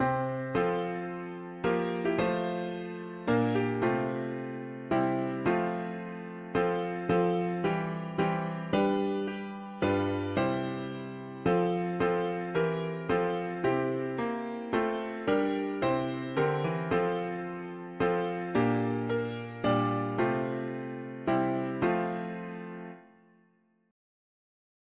Key: F major